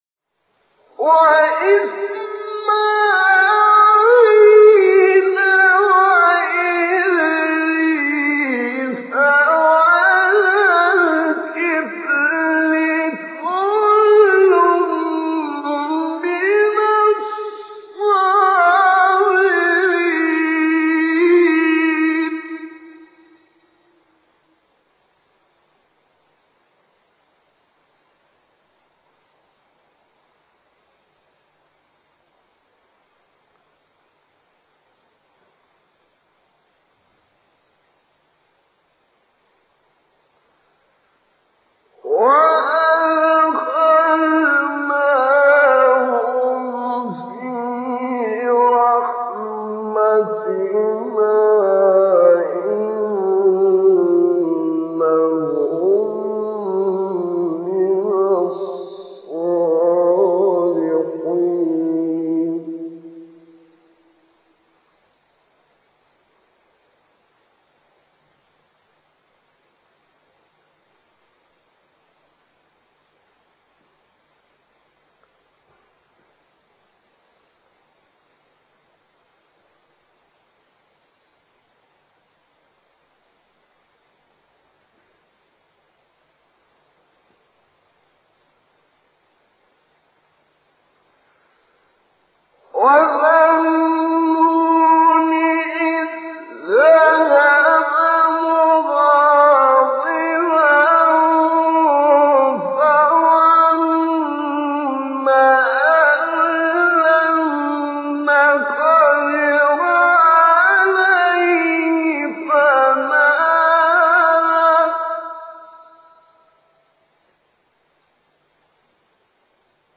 مقام بیات سوره انبیاء استاد شعیشع | نغمات قرآن | دانلود تلاوت قرآن